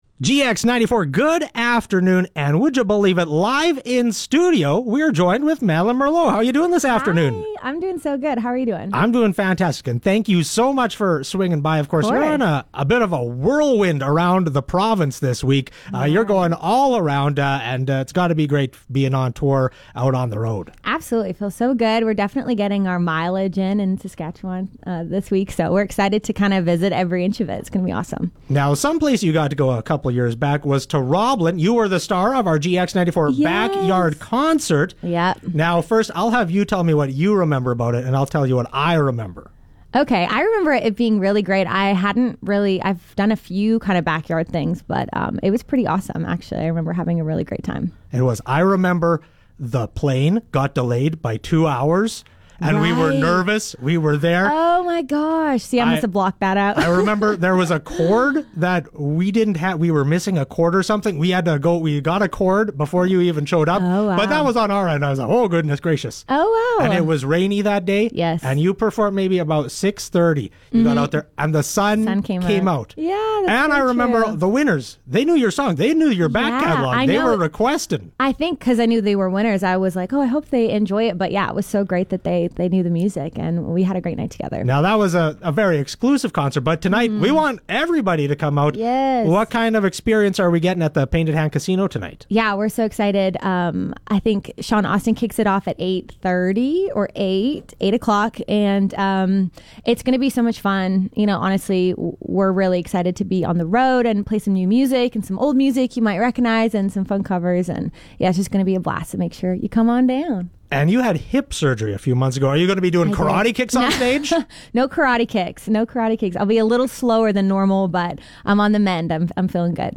Interview with Madeline Merlo